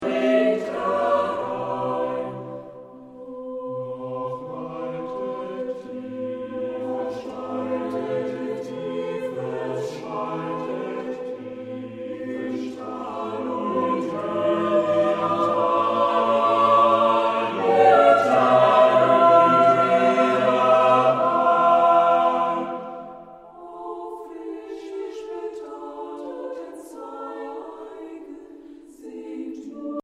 Eine Aufnahme überwiegend romantischer Chorliteratur
klangschönen und nuancenreichen Gesang